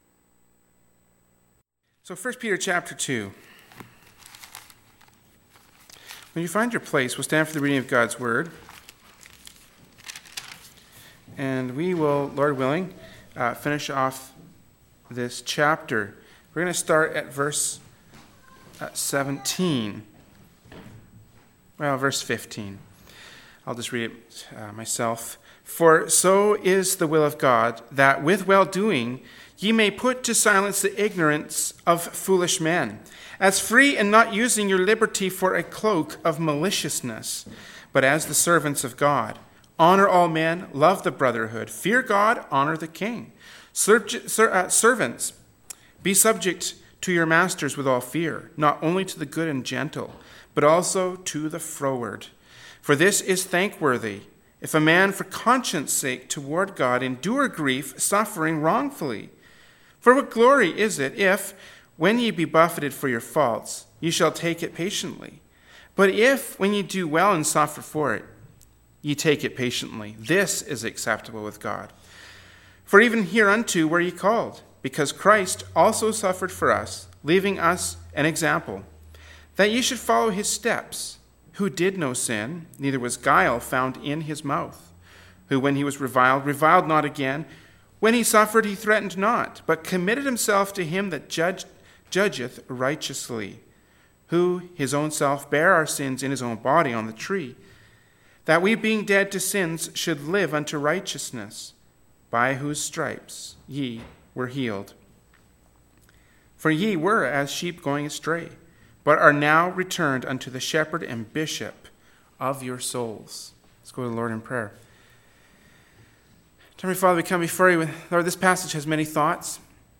“1st Peter 2:15-25” from Wednesday Evening Service by Berean Baptist Church.